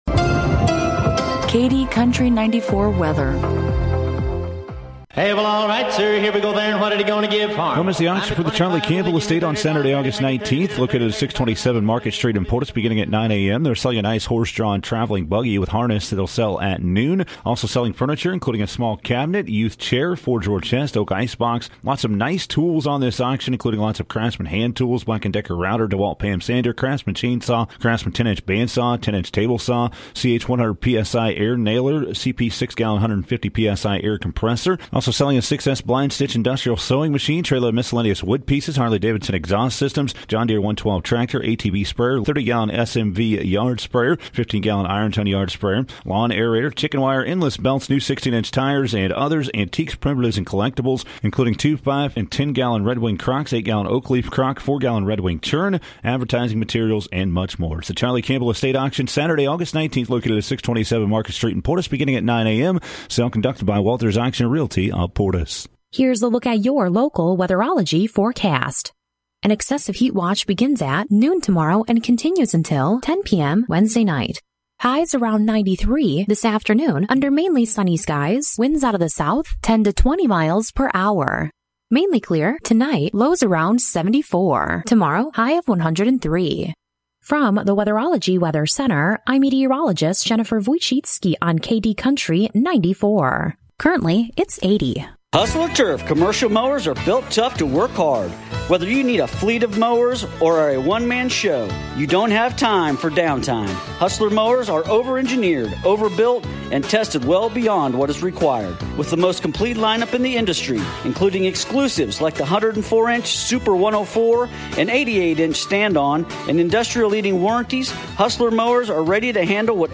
KD Country 94 Local News, Weather & Sports - 8/18/2023